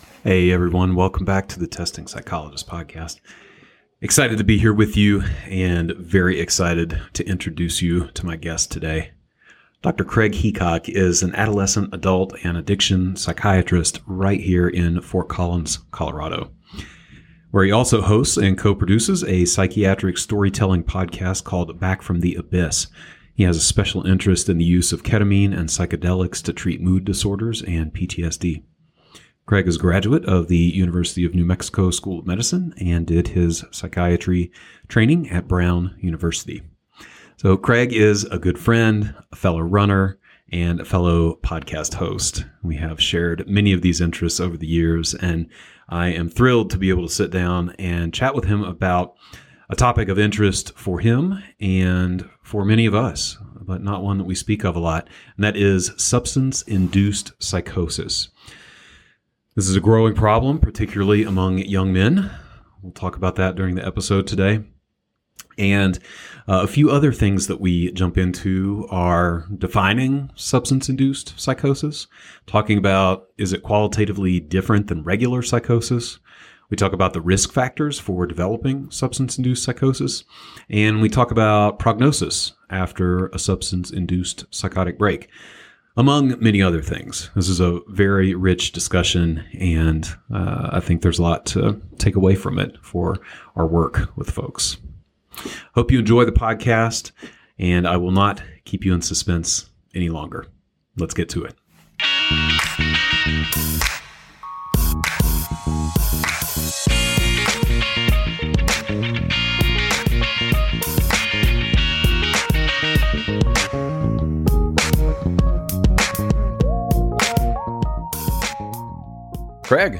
This interview was published in November of 2022.